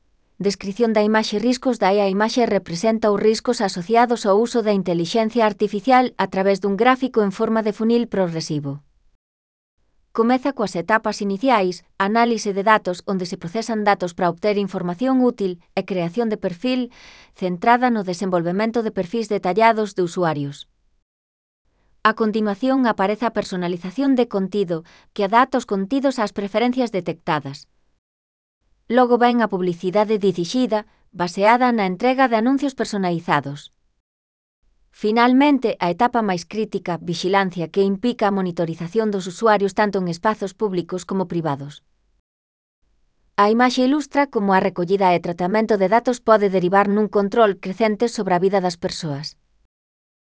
Audio coa descrición da imaxe